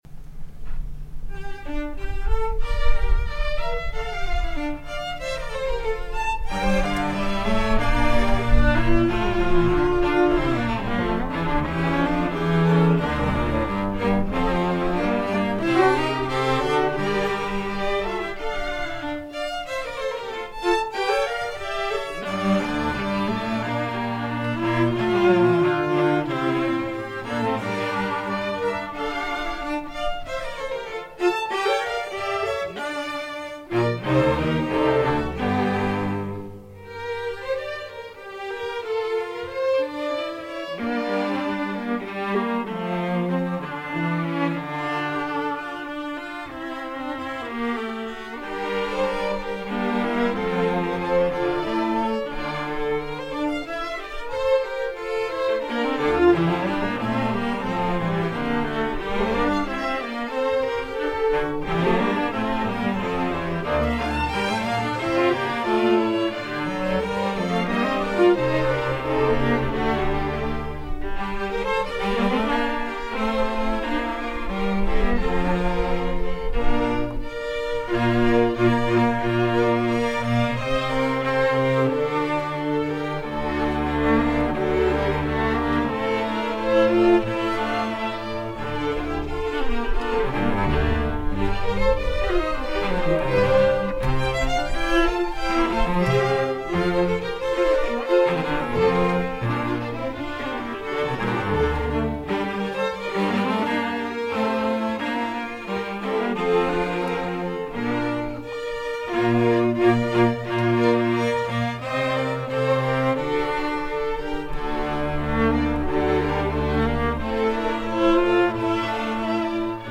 -“La spiritata” για Ορχήστρα Εγχόρδων (live)